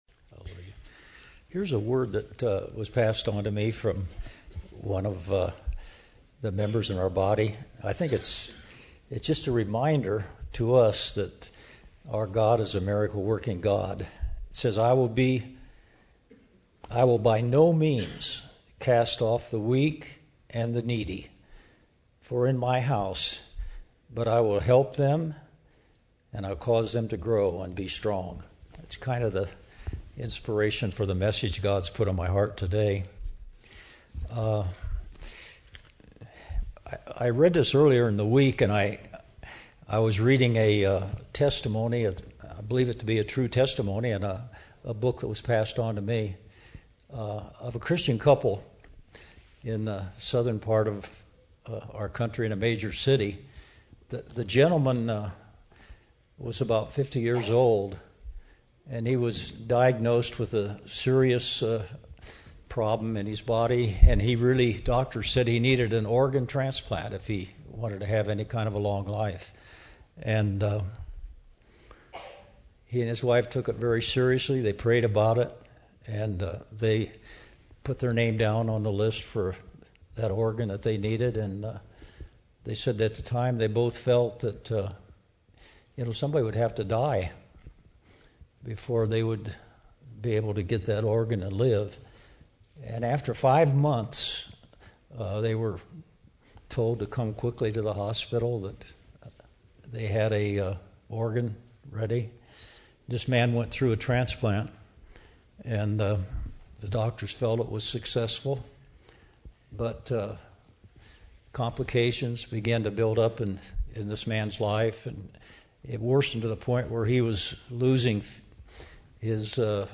A message from the series "Sunday Sermons." A vision of a sick man told him not to give up.